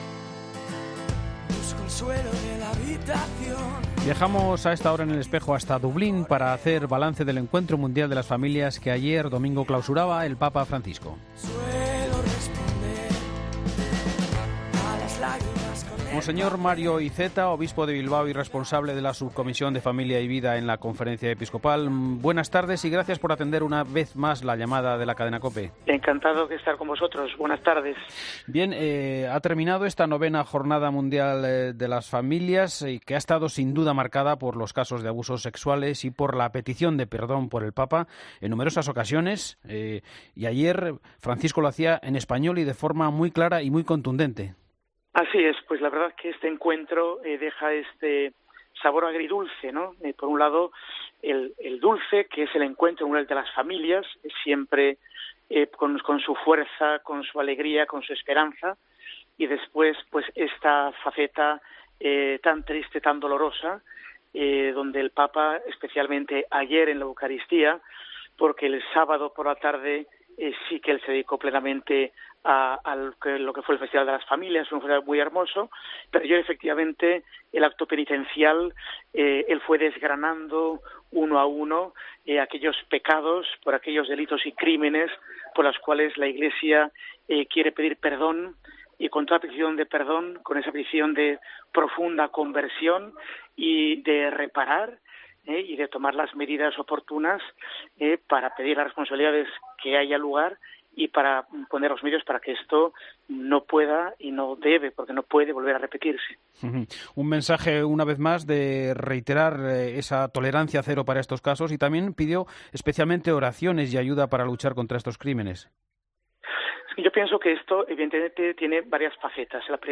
AUDIO: Mons. Mario Iceta, obispo de Bilbao ha hecho un balance en El Espejo sobre el Encuentro Mundial de las Familias